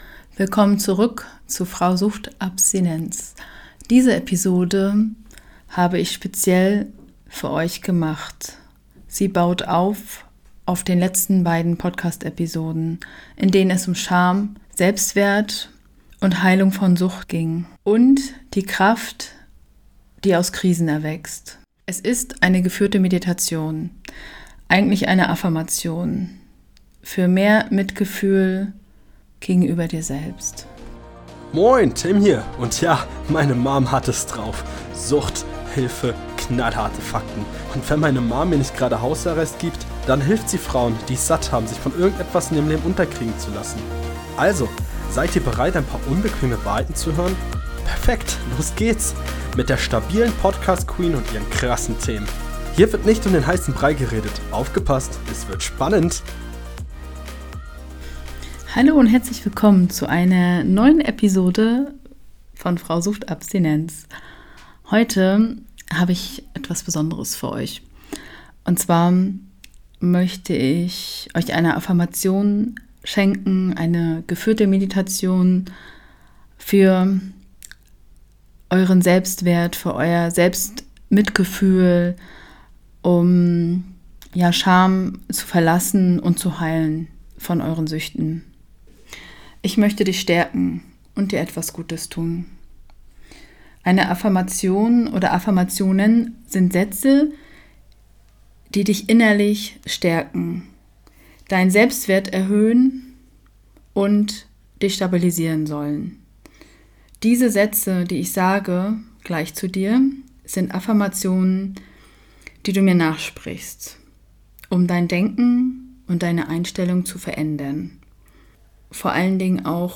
Diese geführte Meditation ist eine Affirmation, die darauf abzielt, dein Selbstwertgefühl zu stärken und dir zu helfen, mehr Mitgefühl für dich selbst zu entwickeln. Sie baut auf den letzten beiden Episoden auf, in denen wir über Scham, Selbstwert und die Kraft der Krisen gesprochen haben.
97_meditation_affirmation_heilung_sucht.mp3